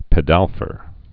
(pĭ-dălfər)